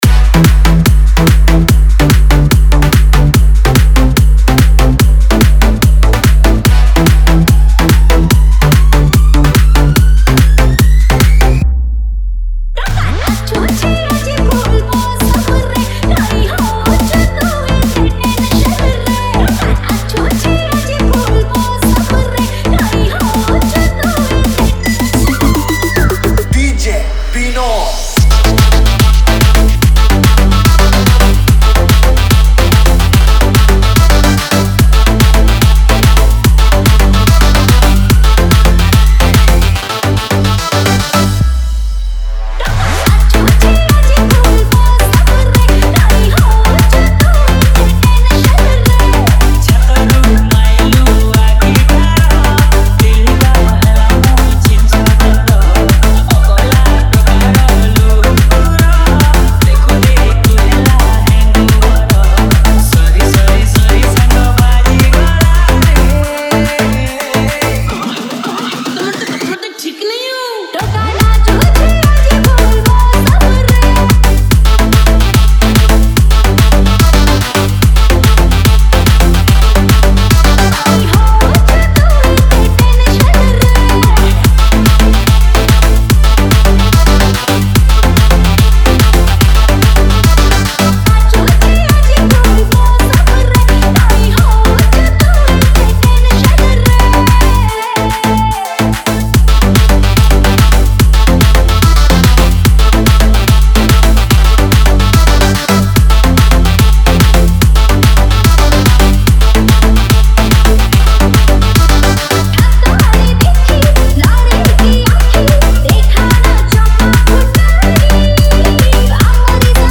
Edm Dance Mix